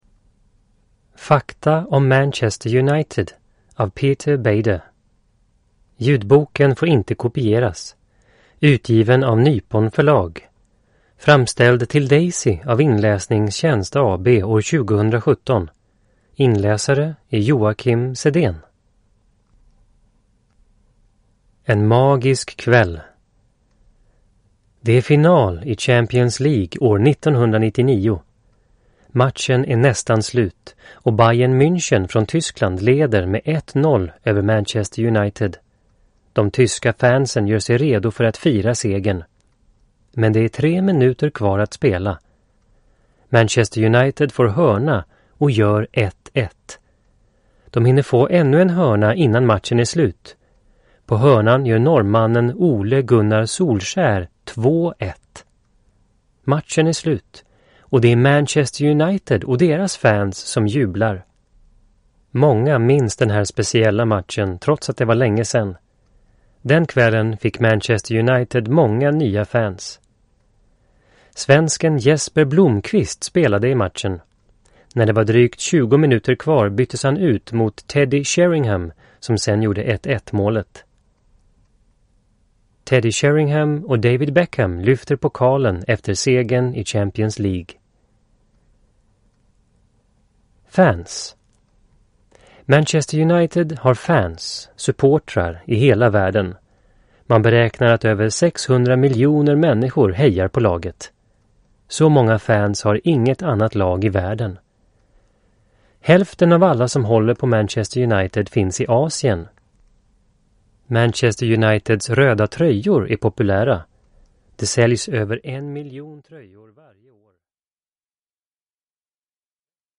Fakta om Manchester United (ljudbok) av Peter Bejder